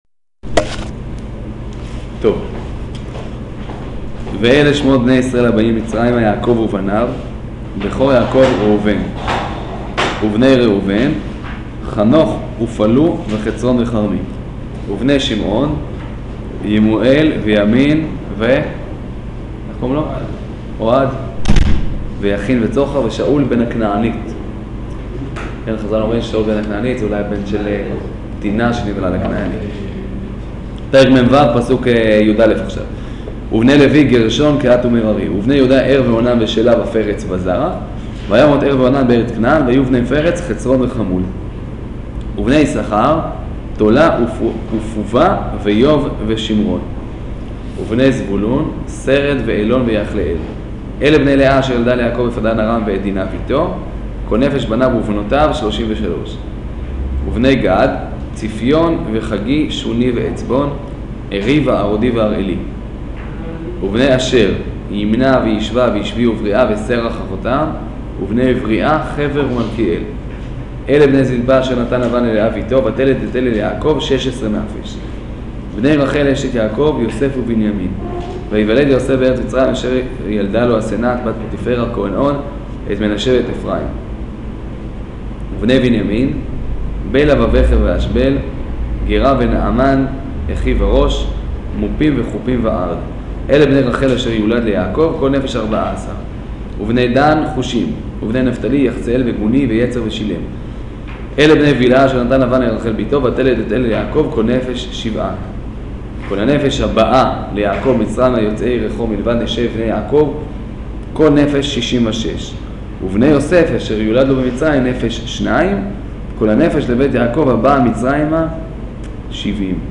שיעור פרשת ויגש